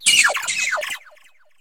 Cri de Taupikeau dans Pokémon HOME.